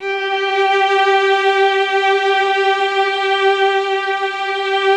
MELLOTRON .7.wav